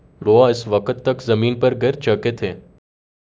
deepfake_detection_dataset_urdu / Spoofed_TTS /Speaker_11 /16.wav